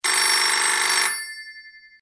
telephone